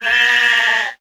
Cri de Moumouflon dans Pokémon HOME.